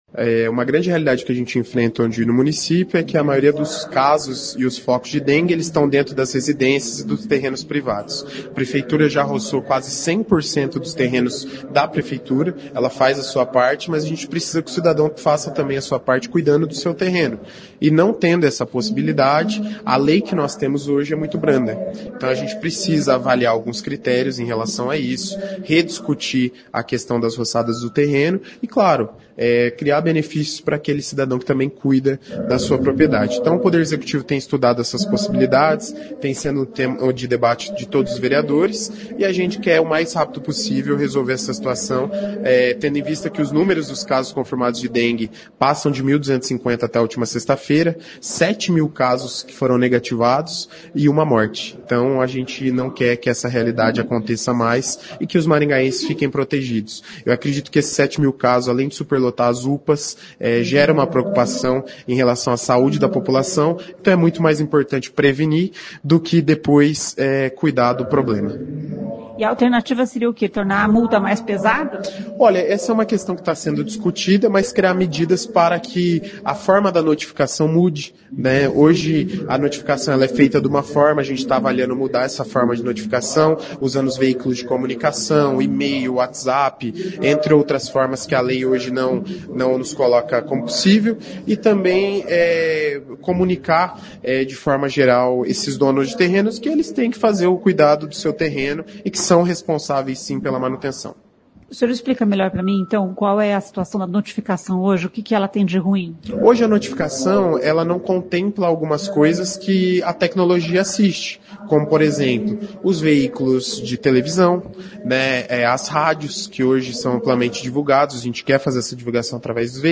Na sessão da Câmara Municipal de Maringá nesta terça-feira (15), o vereador Luiz Neto, líder do prefeito no Legislativo, falou sobre a questão das roçadas no município.